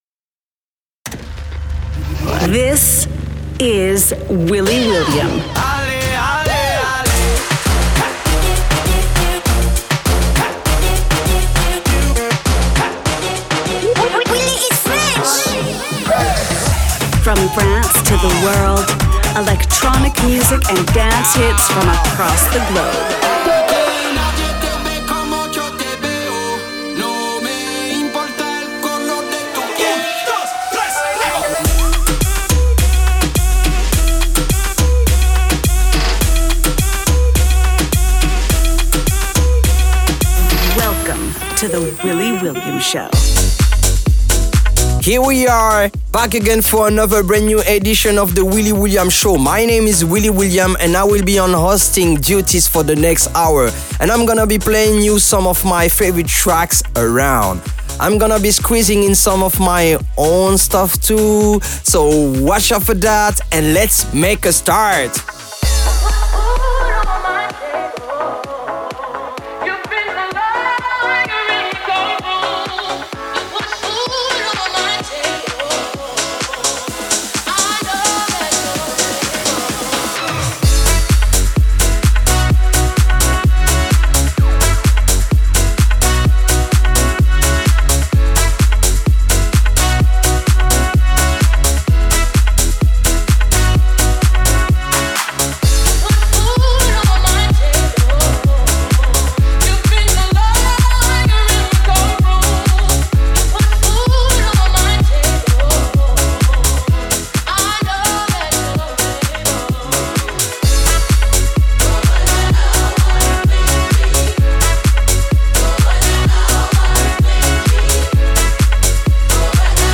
Also find other EDM Livesets, DJ Mixes and Radio Show
a weekly radio-show